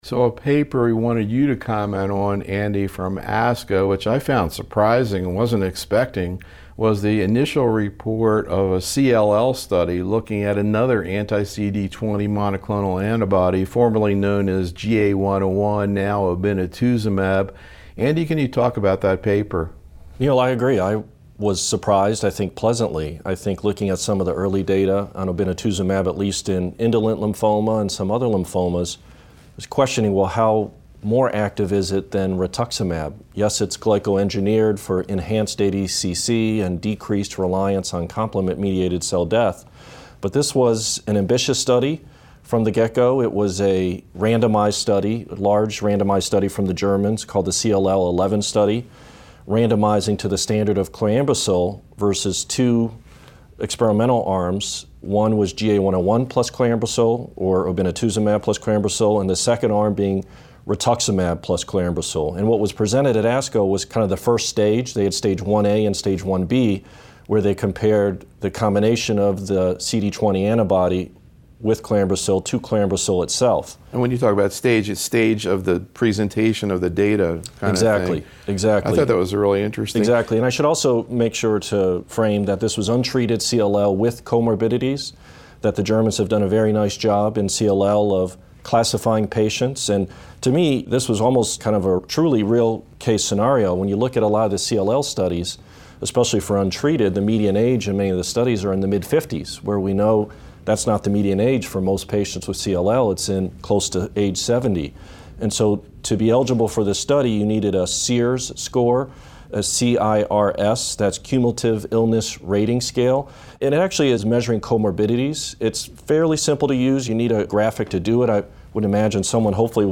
The roundtable discussion focused on key presentations and papers and actual cases managed in the practices of the faculty where these data sets factored into their decision-making.